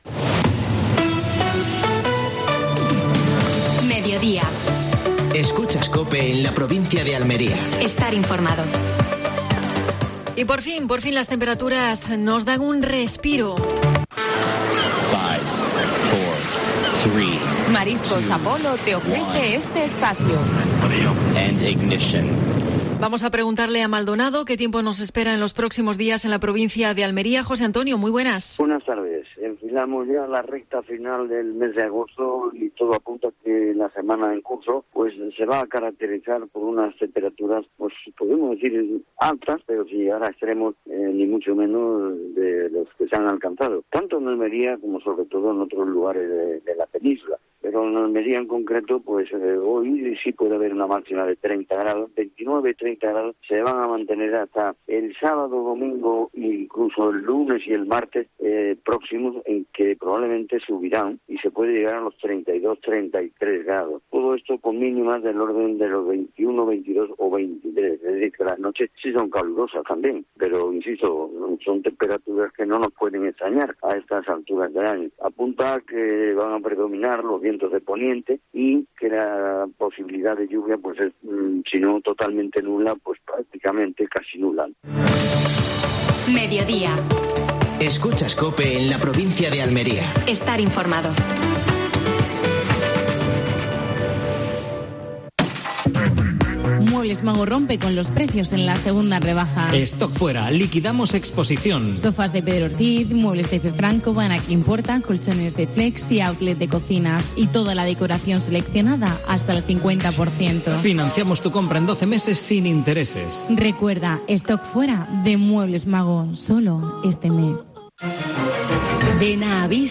Actualidad en Almería. Entrevista